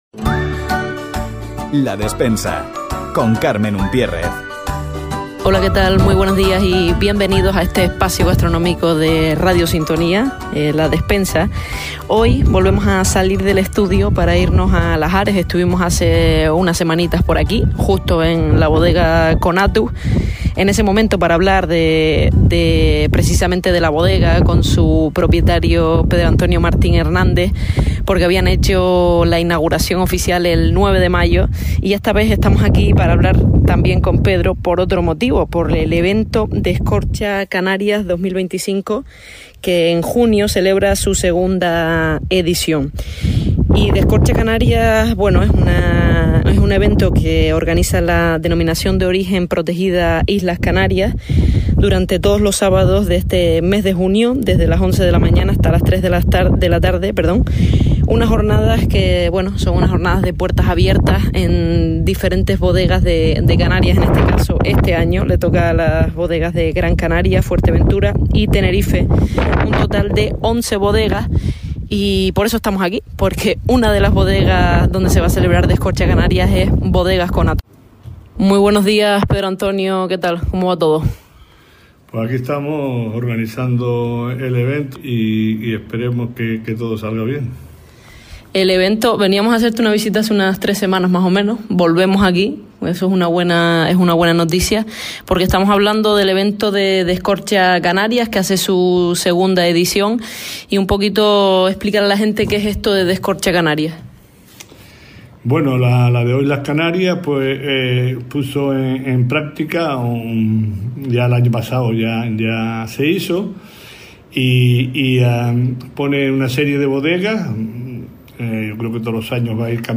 Hemos tenido el placer de entrevistar